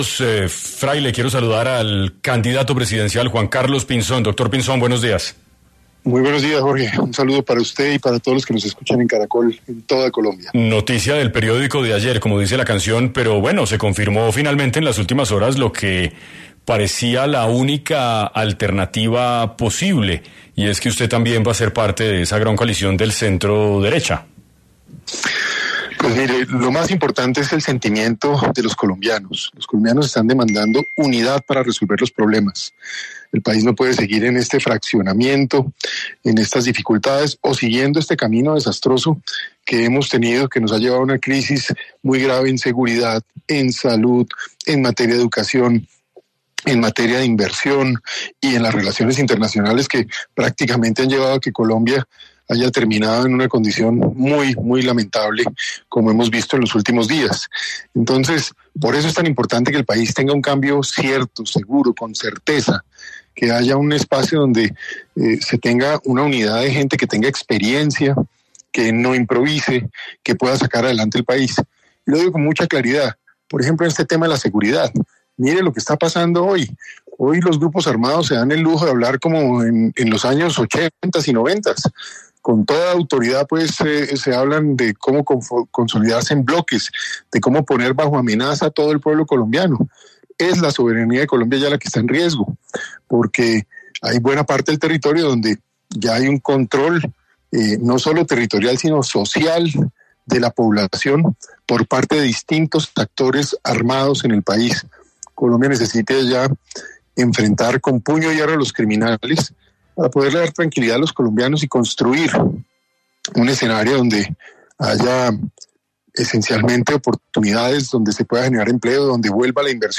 El exministro de Defensa habló en 6AM sobre su perspectiva de cara a la consulta que se llevará a cabo el próximo 8 de marzo.